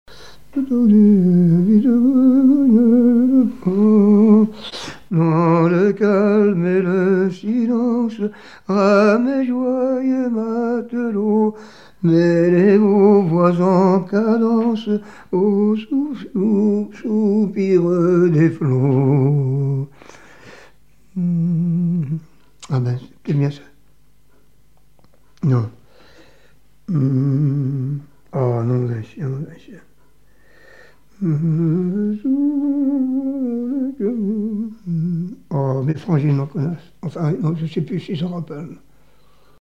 Genre strophique
témoignages et chansons maritimes
Pièce musicale inédite